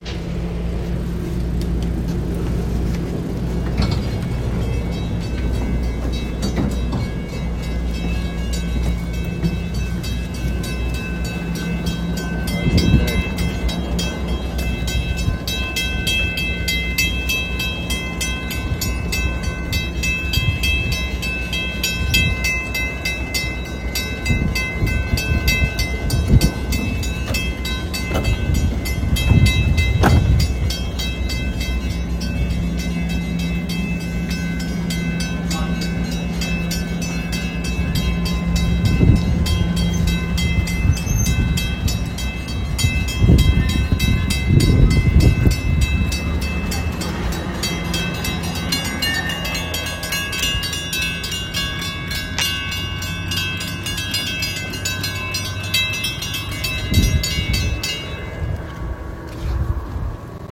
Field Recording #3
Location: Hawthorne Train Station
Sounds: Door opening, train engine, train moving, station bells